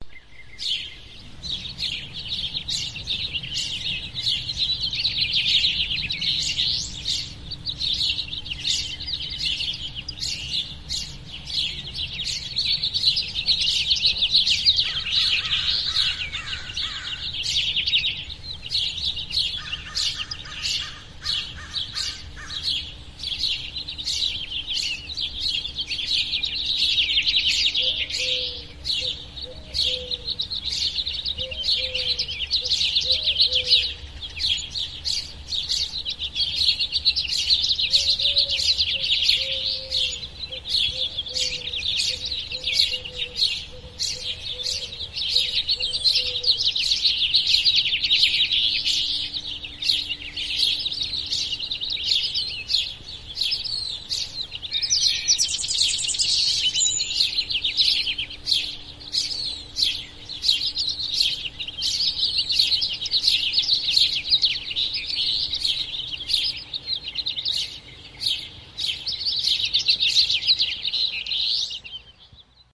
Пение птиц в теплый день у соседнего дома